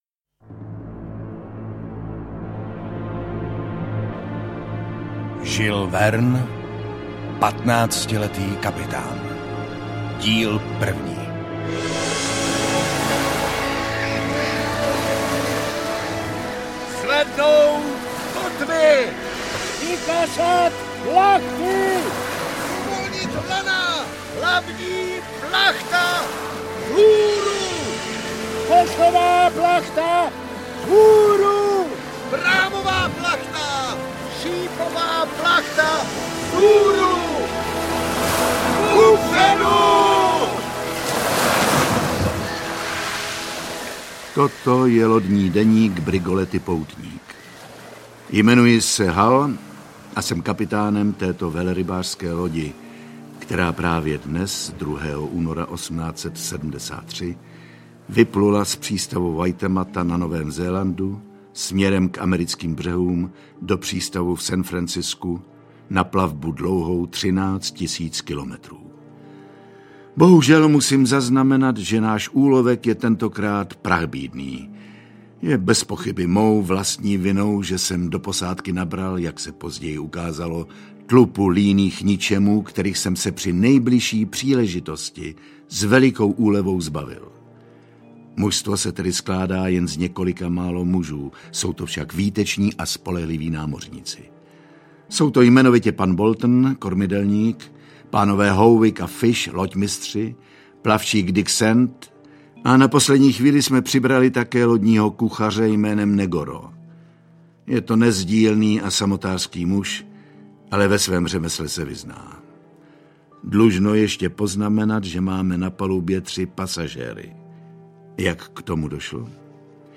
Patnáctiletý kapitán audiokniha
Audio kniha
Ukázka z knihy